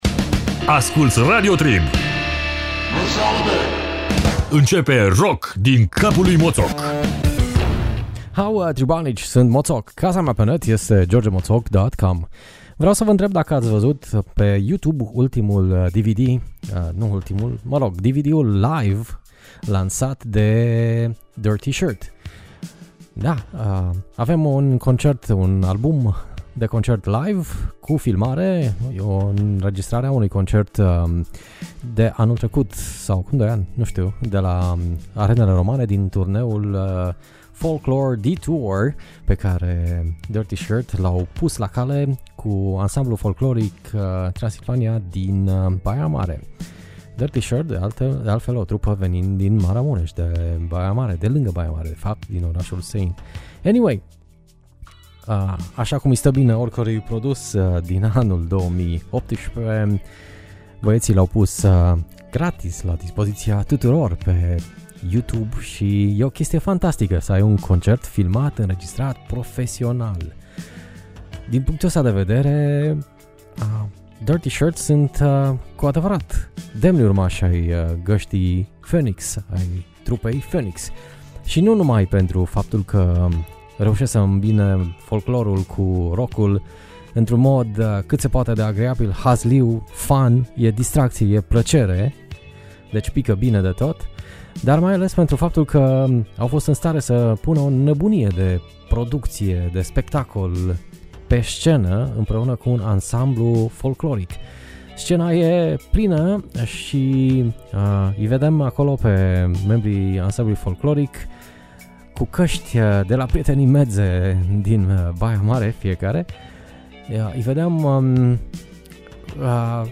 Afrobeat cu fiul cel mic al celui care a băgat genul în dicționar, Fela Kuti.